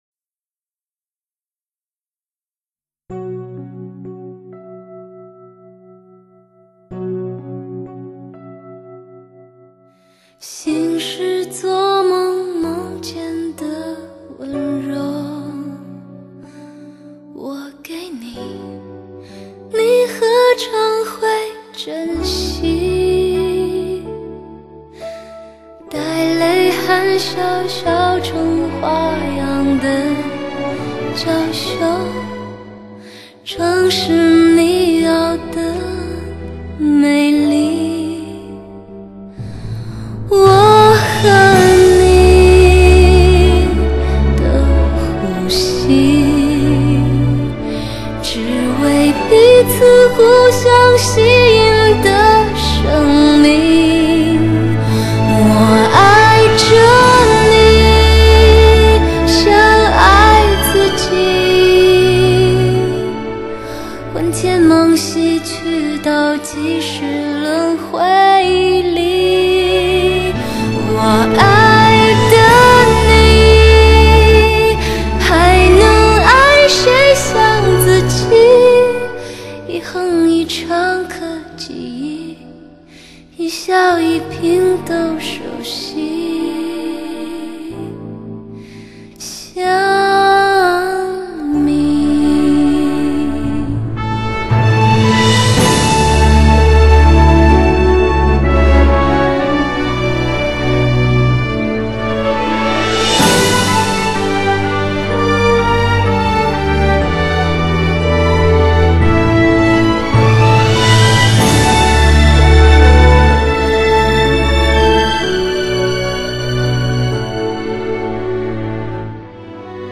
发烧唱片界最经典的DTS CD，经典发烧，不容错过，发烧唱片界最华丽的黄金女声，缥缈声线，华丽绽放。